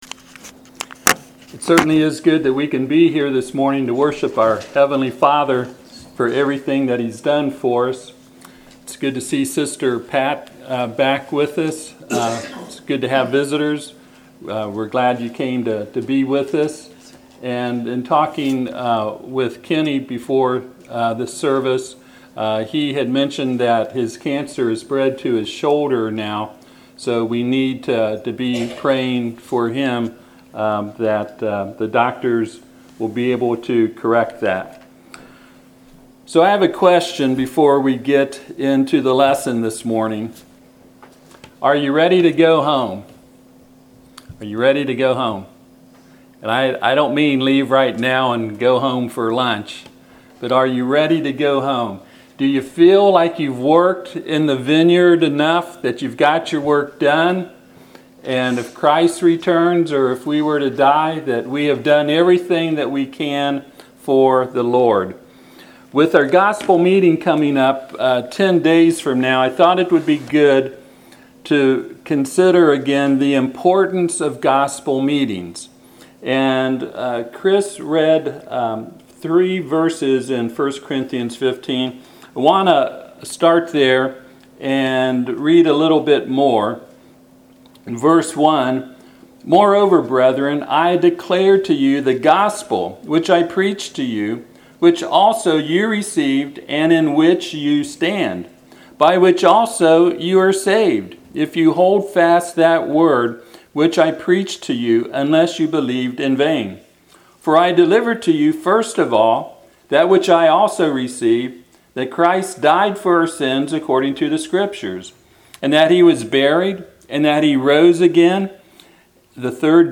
Passage: 1 Corinthians 15:1-11 Service Type: Sunday AM « Parable of the Two Builders What Is My Responsibility To The Local Church?